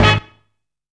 HOT HIT.wav